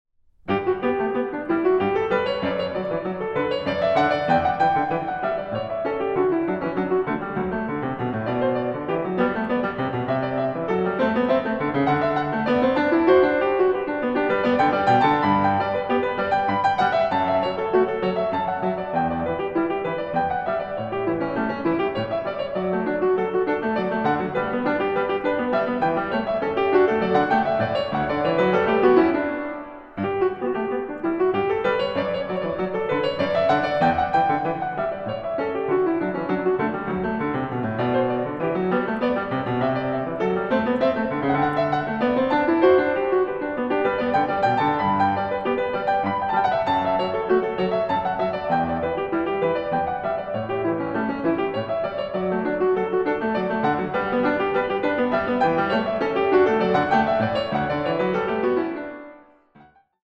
Piano
Recording: Jesus-Christus-Kirche Berlin-Dahlem, 2024